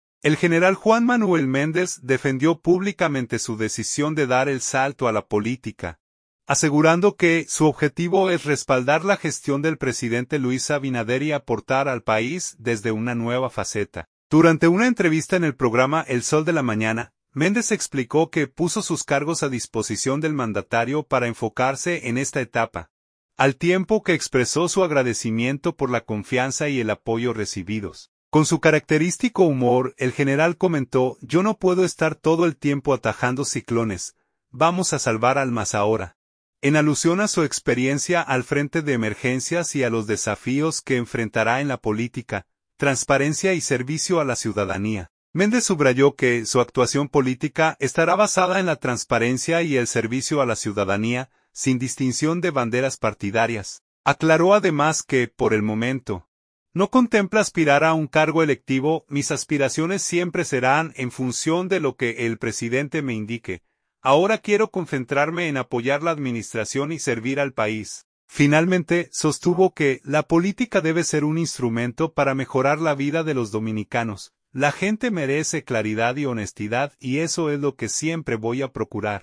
Durante una entrevista en el programa El Sol de la Mañana, Méndez explicó que puso sus cargos a disposición del mandatario para enfocarse en esta etapa, al tiempo que expresó su agradecimiento por la confianza y el apoyo recibidos.